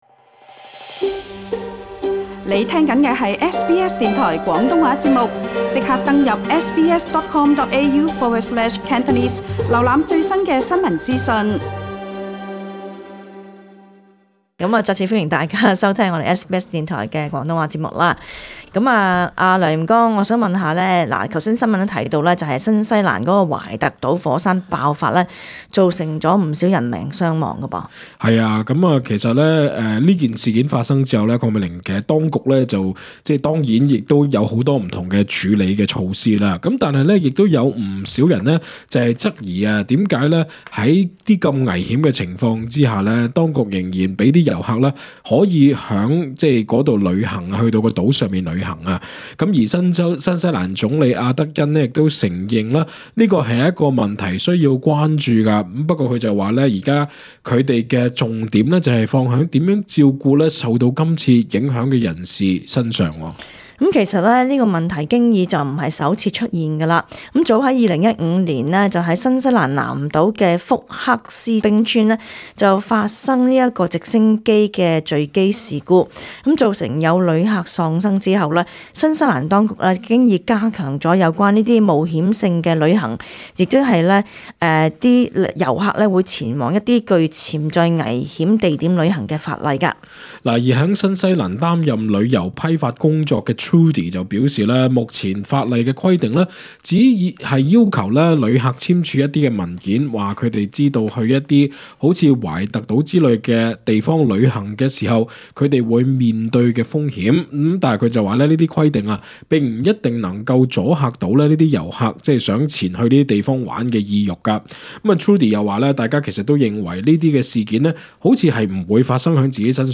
本節目內嘉賓及聽眾意見並不代表本台立場 READ MORE 雪梨應否因山火取消除夕煙花匯演？